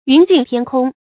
云凈天空 注音： ㄧㄨㄣˊ ㄐㄧㄥˋ ㄊㄧㄢ ㄎㄨㄙ 讀音讀法： 意思解釋： 見「云過天空」。